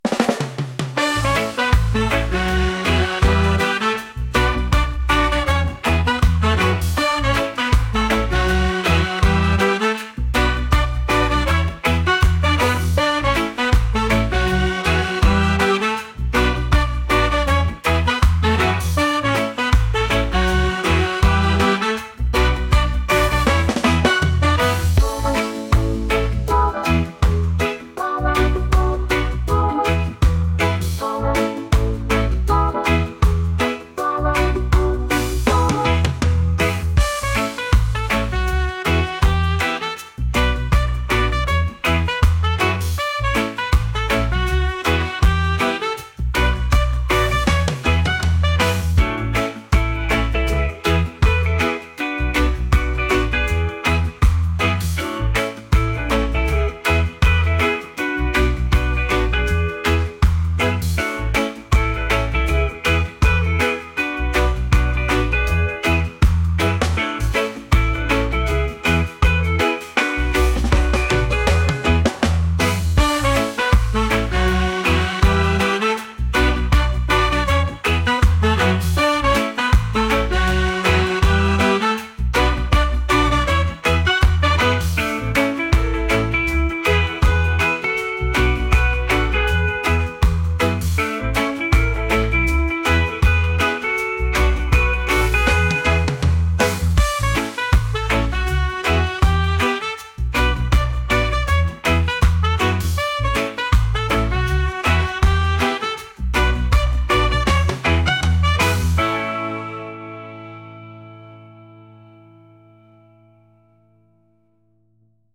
reggae | pop | funk